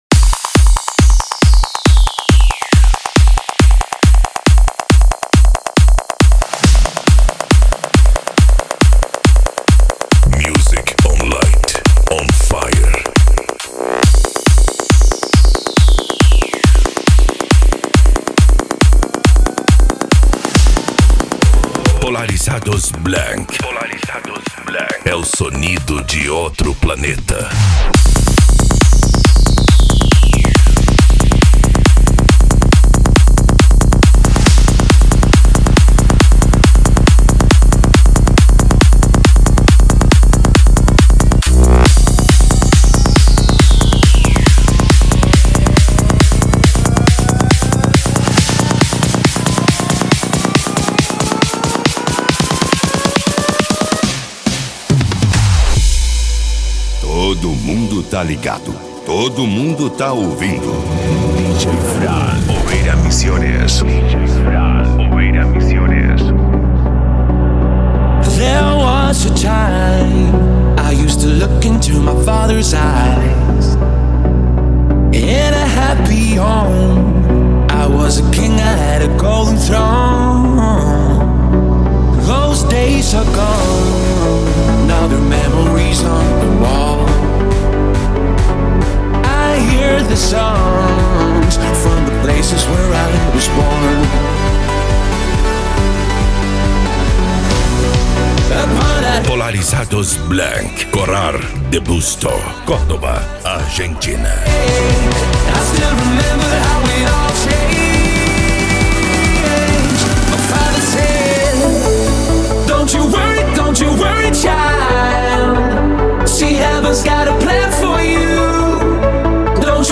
Psy Trance